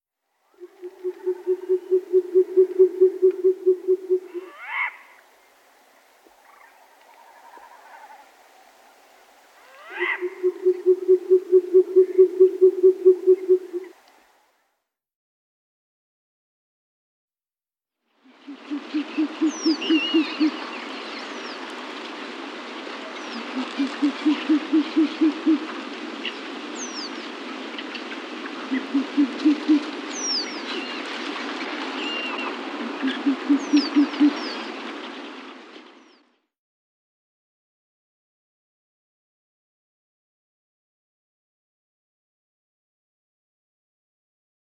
Velduil
Het mannetje roept tijdens de broedtijd “boe-boe-boe-boe-boe-boe-boe“.
velduilzang.mp3